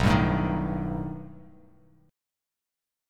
B+7 Chord
Listen to B+7 strummed